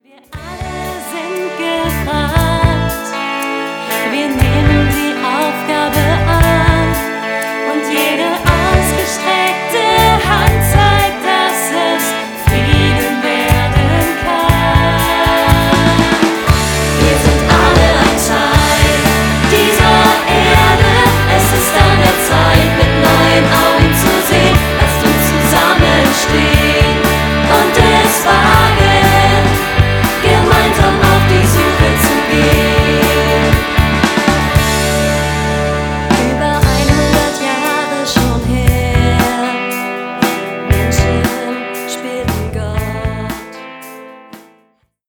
Genre: NGL.